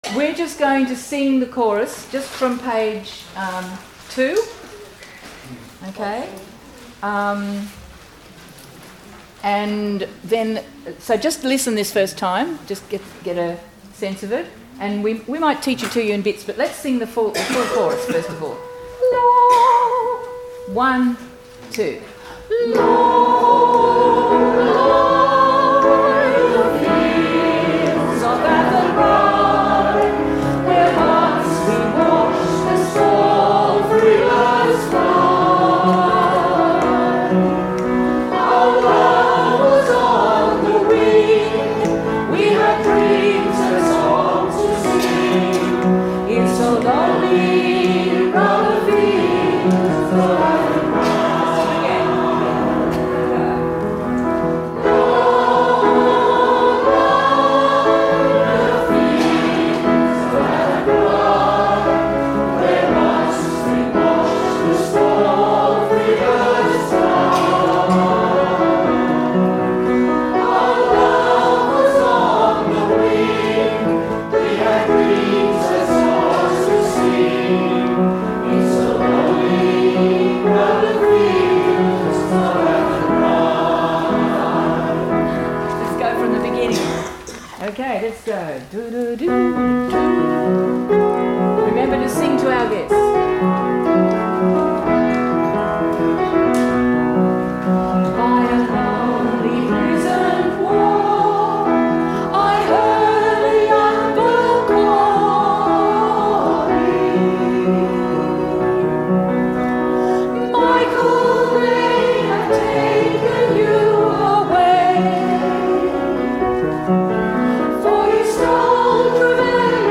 Fields of Athenry which we sang at the Festival.